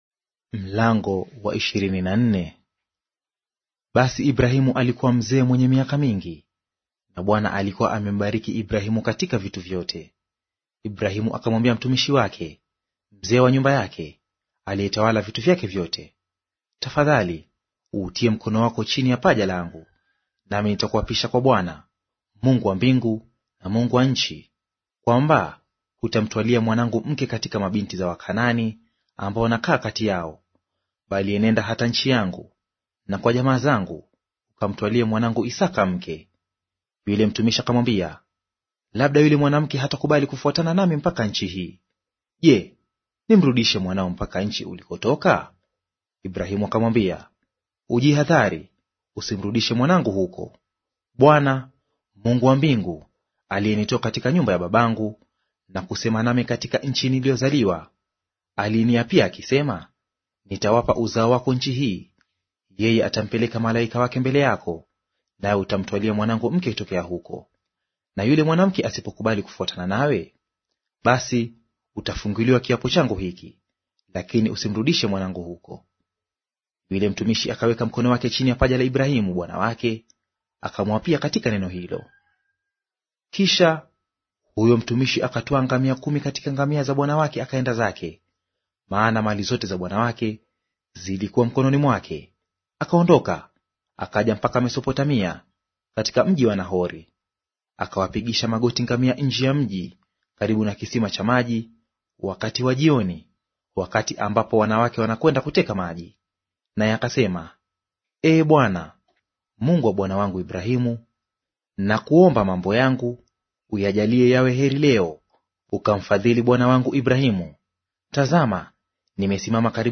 Audio reading of Mwanzo Chapter 24 in Swahili